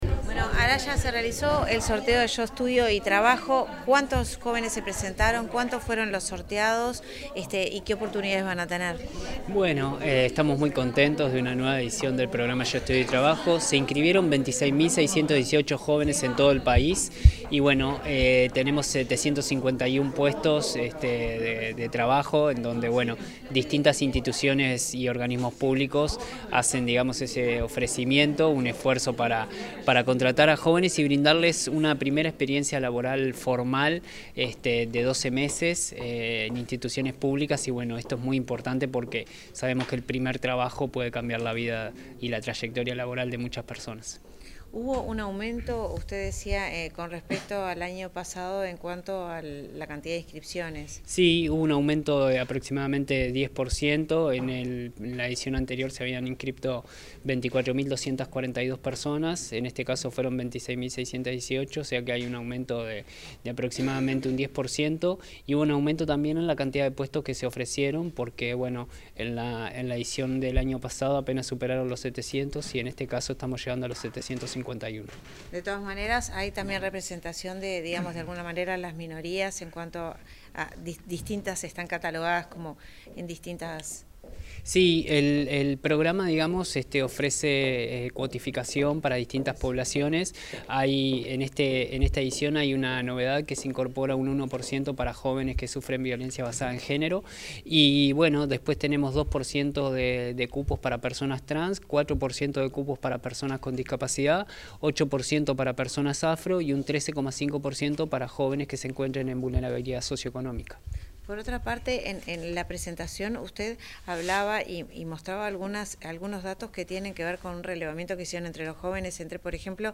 Declaraciones del director nacional de Empleo, Federico Araya
Declaraciones del director nacional de Empleo, Federico Araya 29/10/2025 Compartir Facebook X Copiar enlace WhatsApp LinkedIn Tras el sorteo correspondiente a la 14.ª edición del programa Yo Estudio y Trabajo, el titular de la Dirección Nacional de Empleo, Federico Araya, brindó declaraciones a los medios periodísticos.